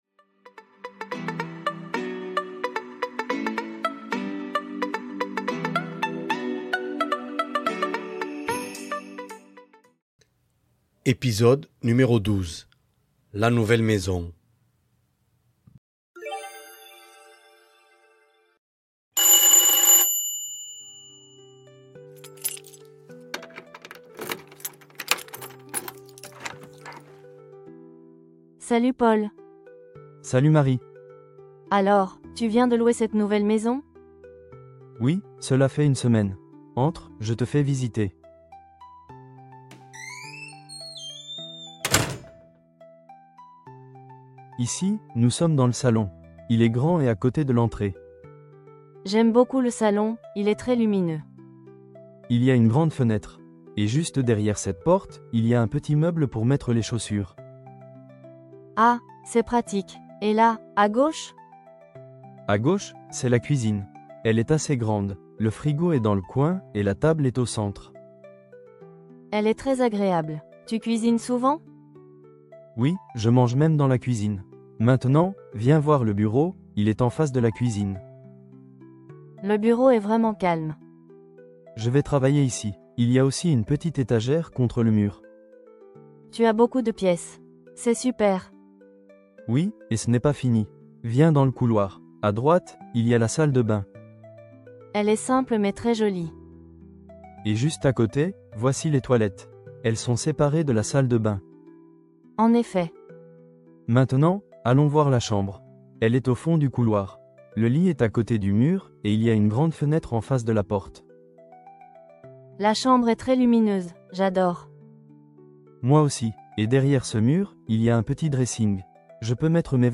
Voici un petit dialogue pour les débutants. Avec cet épisode, vous verrez le vocabulaire de la maison et les prépositions de lieu.